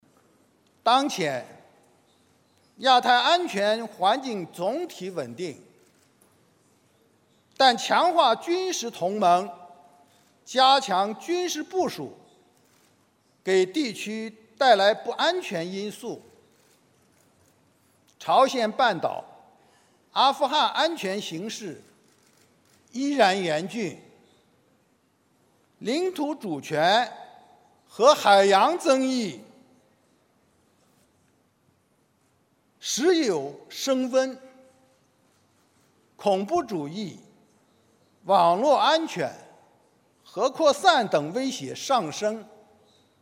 孙建国在香格里拉对话上讲话（1）